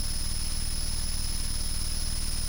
静音合成器II咔嚓声和噪音 " Buzz 006
描述：来自Mute Synth 2的电子嗡嗡声/嗡嗡声。
Tag: 电子 静音-合成器-2 类似物 嗡嗡声 嘟嘟声 嗡嗡声 噪声 静音-合成器-II